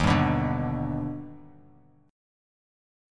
Eb+M7 chord